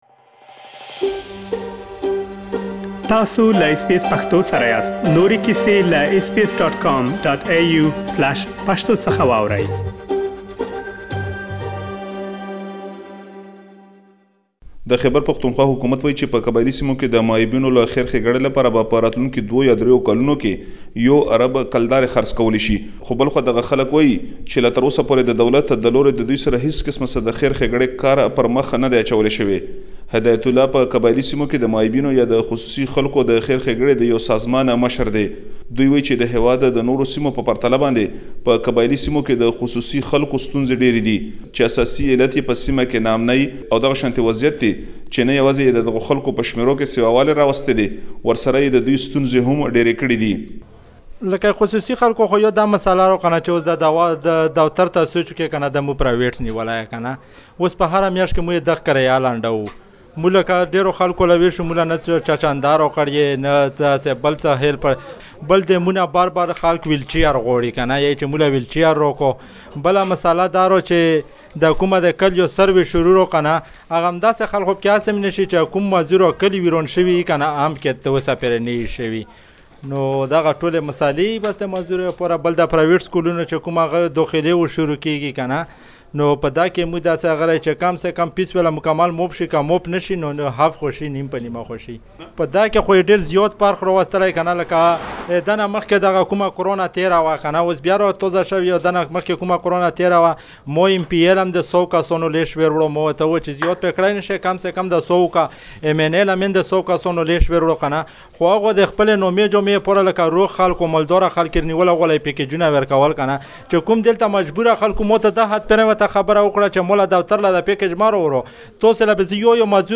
له معلولينو مدافعينو او دولتي چارواکو سره مرکې کړي چې دا ټول پدې تيار شوي رپوټ کې اوريدلی شئ.